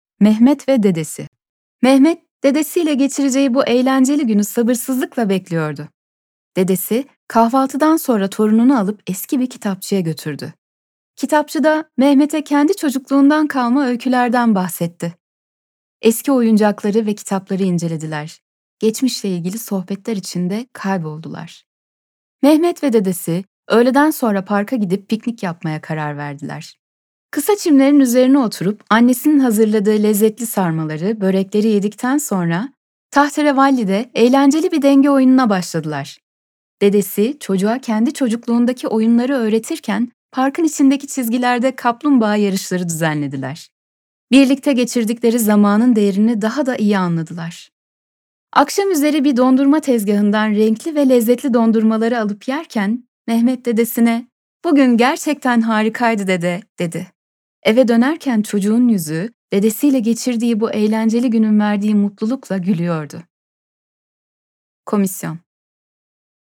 Dinleme Metni